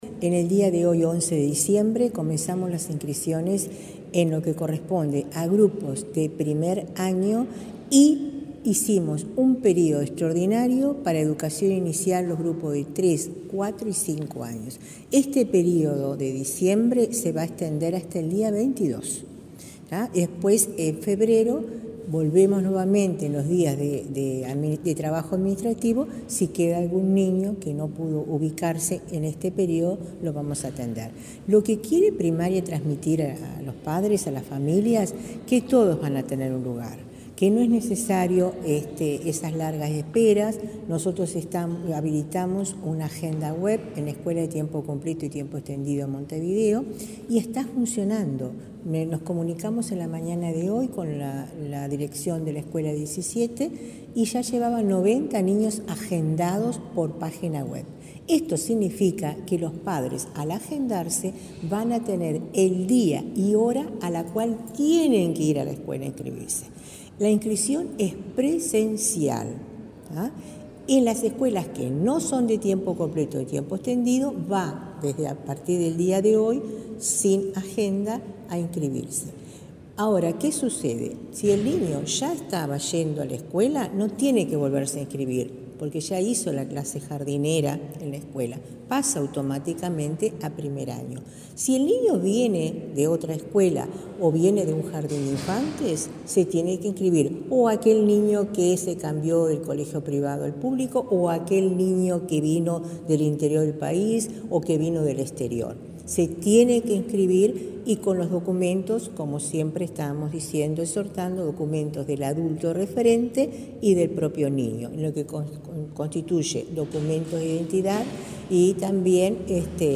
Entrevista a Olga de las Heras de ANEP